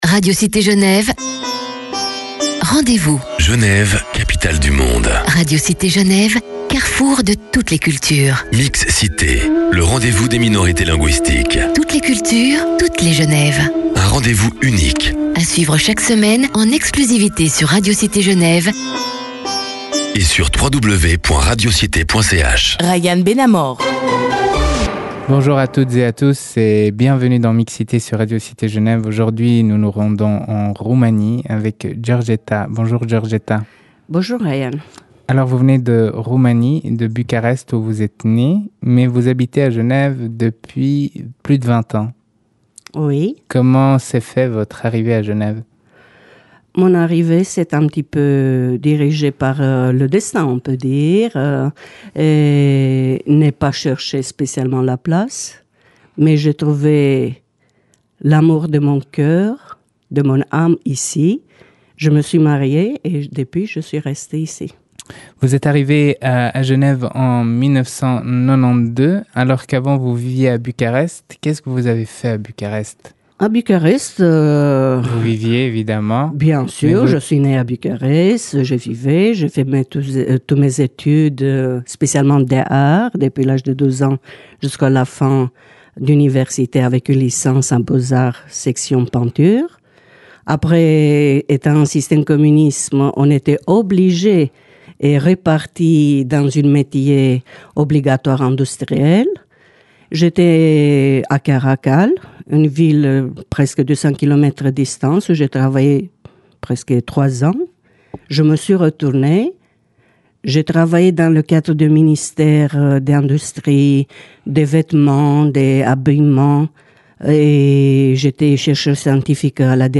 R A D I O C I T É G E N É V E - interview 2013